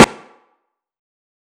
SNARE RVERB.wav